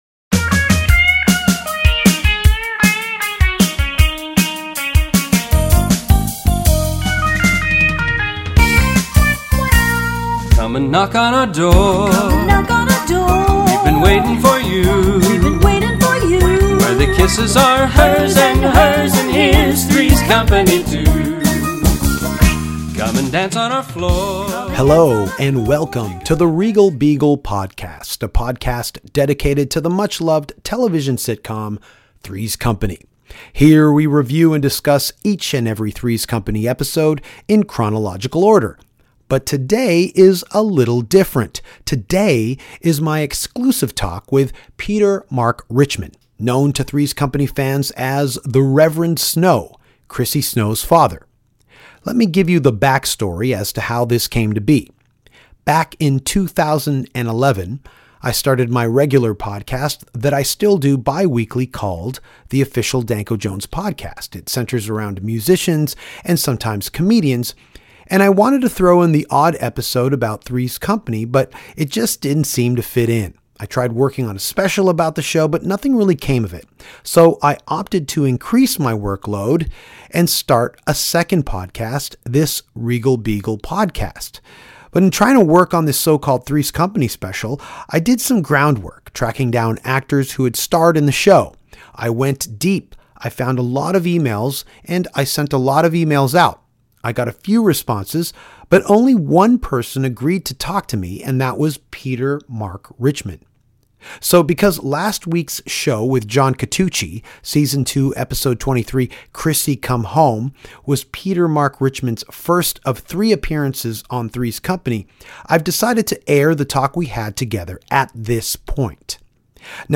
Listen to this exclusive chat with a Three's Company legend!